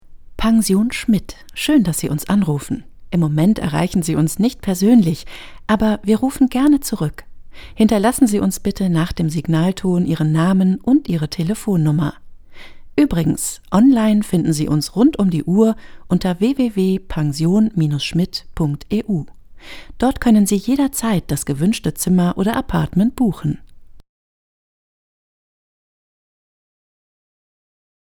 norddeutsch
Sprechprobe: eLearning (Muttersprache):